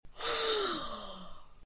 yawn1.wav